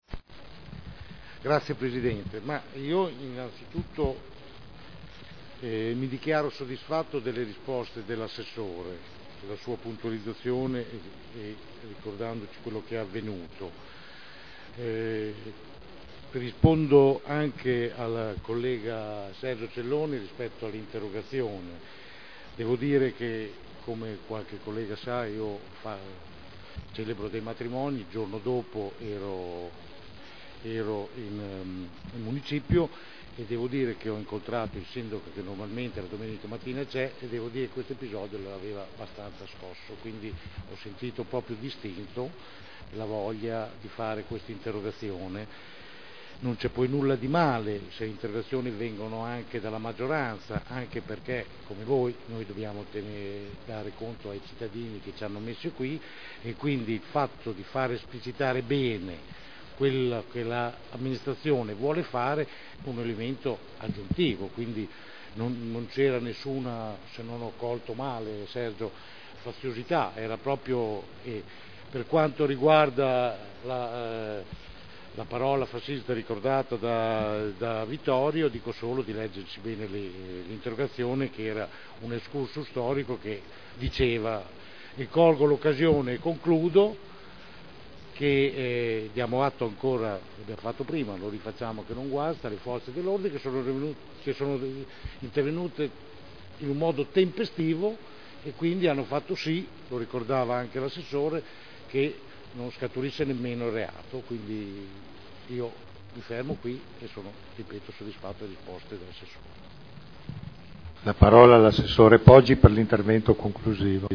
Francesco Rocco — Sito Audio Consiglio Comunale